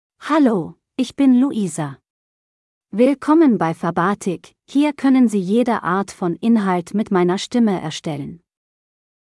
Louisa — Female German (Germany) AI Voice | TTS, Voice Cloning & Video | Verbatik AI
FemaleGerman (Germany)
Louisa is a female AI voice for German (Germany).
Voice sample
Listen to Louisa's female German voice.
Louisa delivers clear pronunciation with authentic Germany German intonation, making your content sound professionally produced.